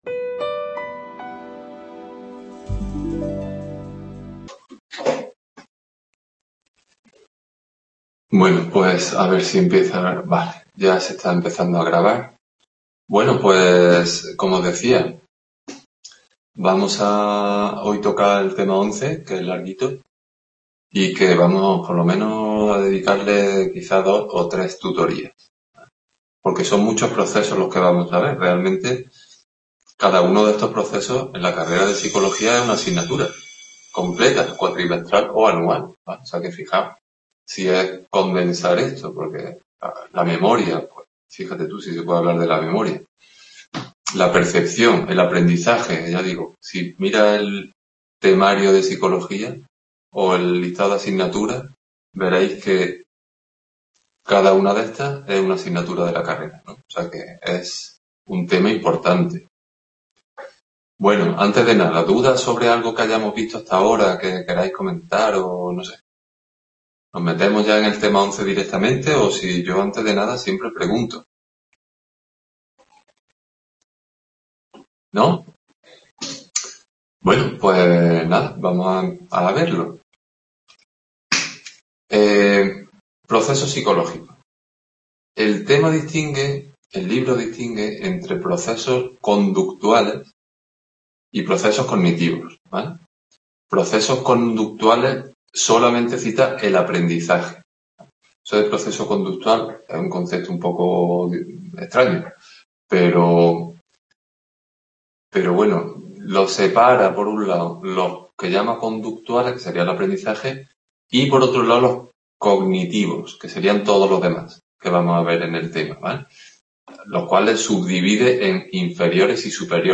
Aprendizaje Description Tutoría de Psicología del Curso de Acceso en Córdoba.